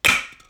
Treffer.ogg